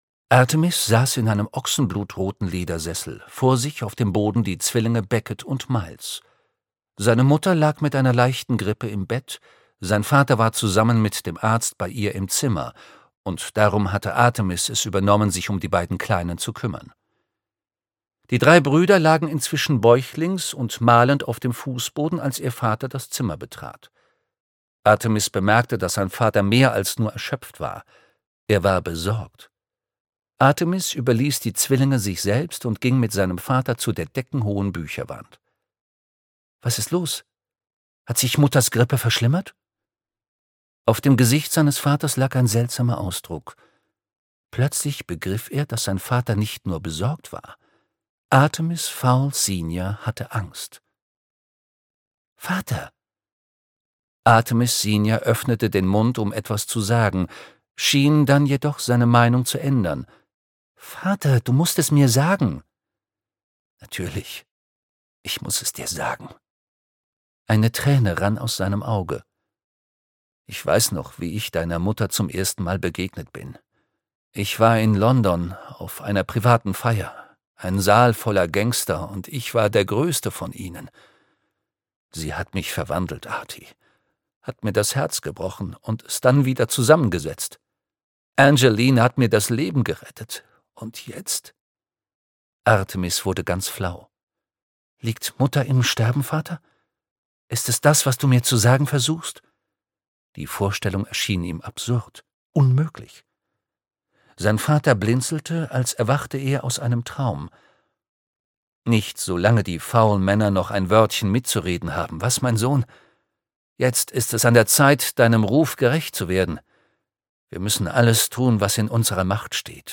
Artemis Fowl - Das Zeitparadox (Ein Artemis-Fowl-Roman 6) - Eoin Colfer - Hörbuch